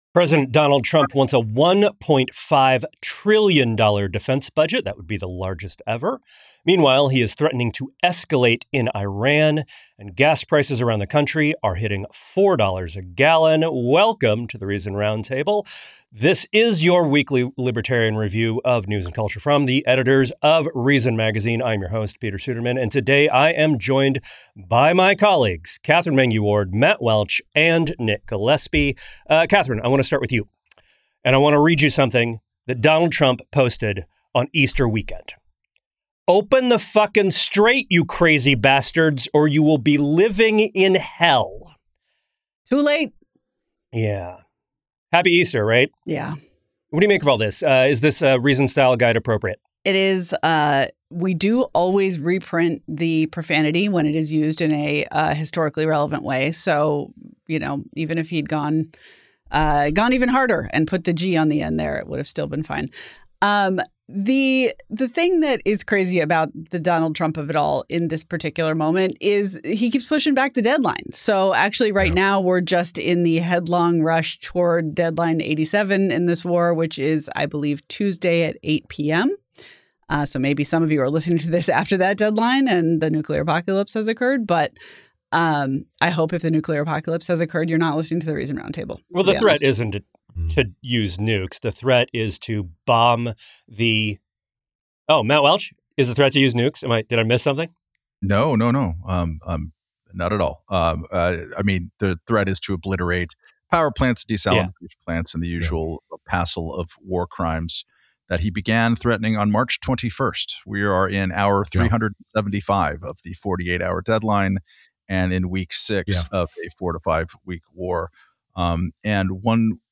The panel also weighs Trump's proposal for a $1.5 trillion defense budget, the largest in modern history, and what it says about the administration's priorities. Next, the panel turns to the broader federal budget, where rising spending continues without any serious attempt to address the deficit.